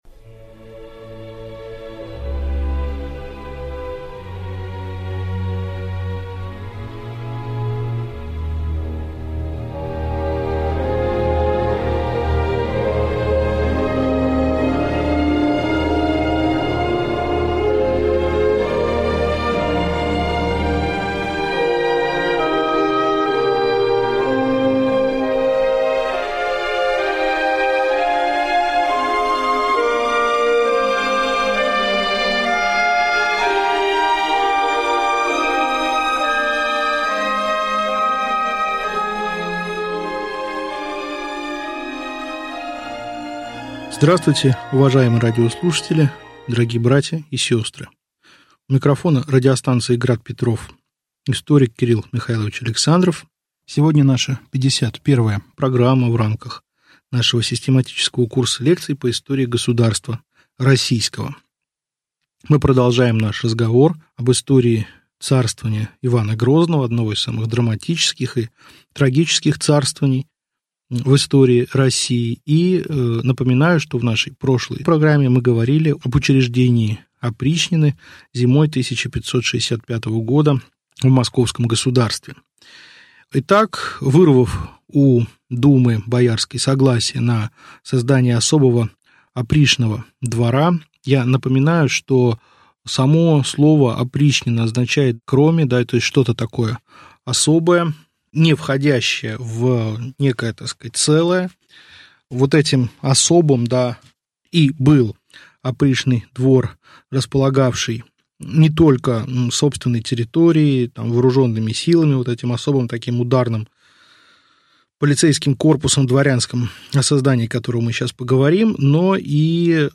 Аудиокнига Лекция 51. Опричнина и Земщина | Библиотека аудиокниг